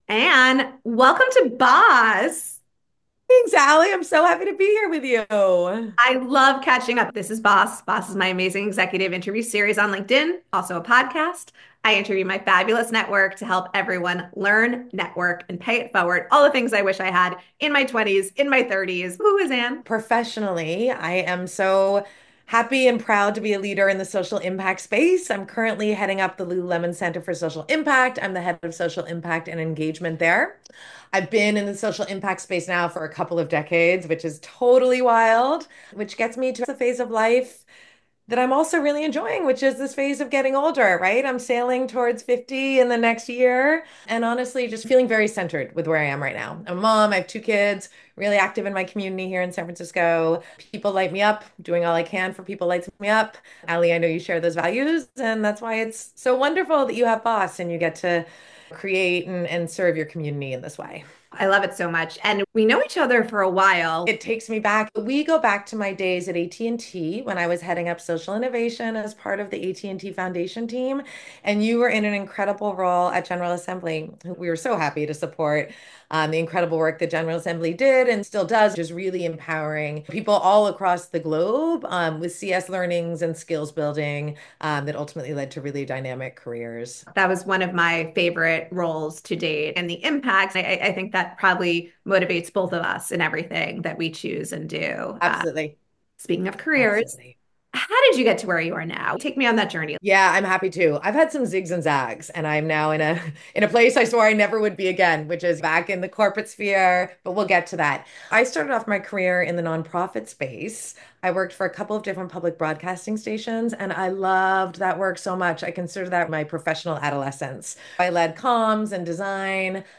This isn’t just another interview—it’s YOUR blueprint for driving real, impactful change in any organization.